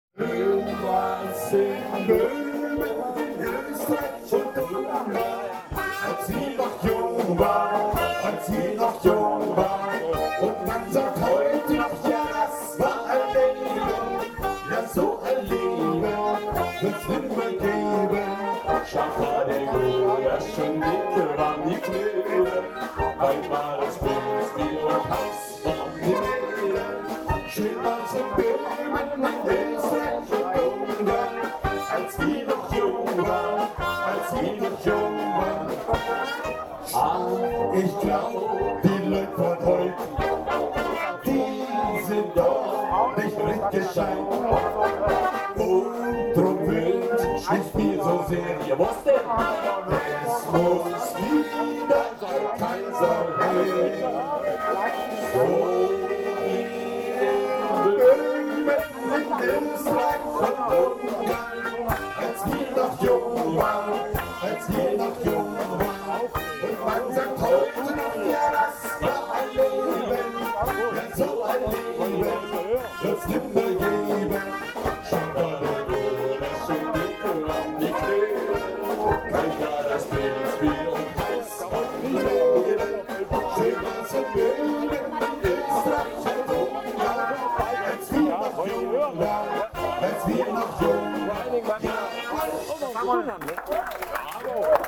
Eichsfeld Musikanten und die Breitenhölzer Feuerwehrblaskapelle.
Auch wenn es am Nachmittag zu regnen begonnen hatte, die Kapellen sowie die Gäste sitzen alle im Trockenen, dank des eigenen wasserdichten Zeltes, dass die Feuerwehrleute vor Jahren selbst angefertigt haben.